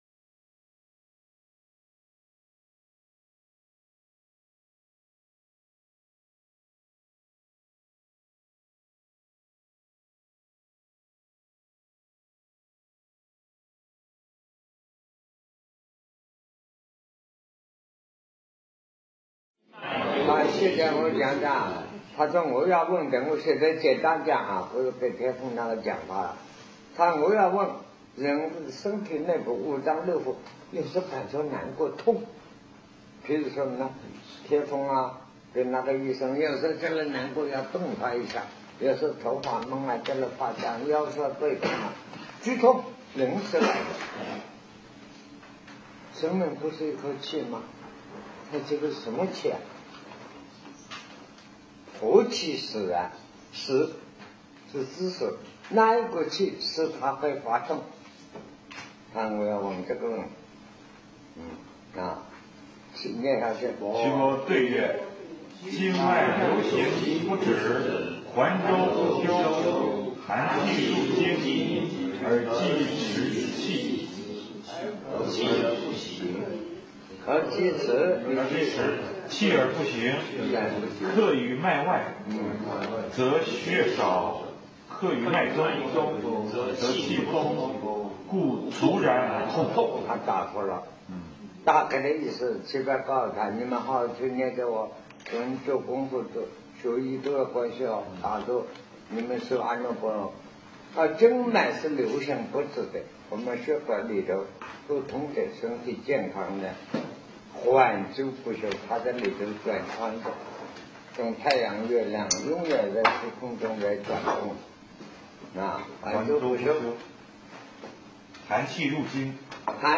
南師講《黃帝內經·舉痛論》03